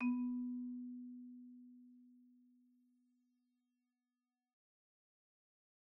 Marimba_hit_Outrigger_B2_loud_01.wav